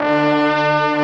BRASS 1-L.wav